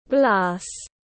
Thủy tinh tiếng anh gọi là glass, phiên âm tiếng anh đọc là /ɡlæs/.
Glass /ɡlæs/
Glass.mp3